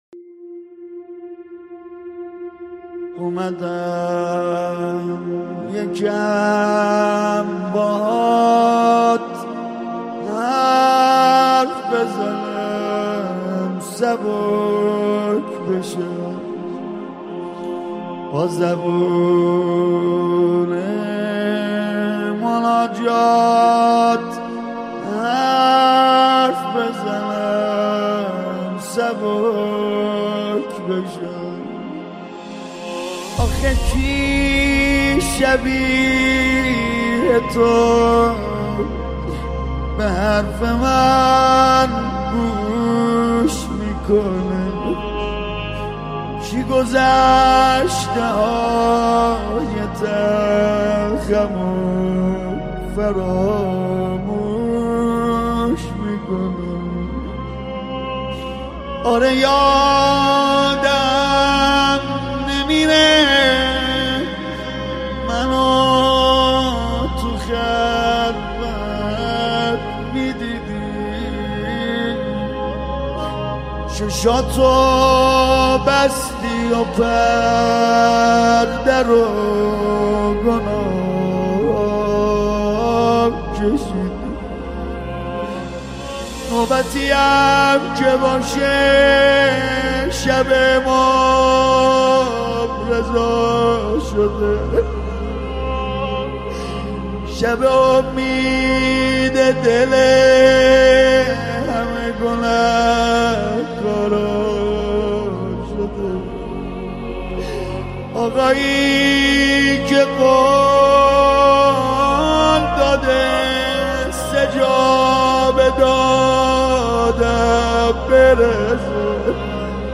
نماهنگ بسیار زیبا و شنیدنی
با صدای دلنشین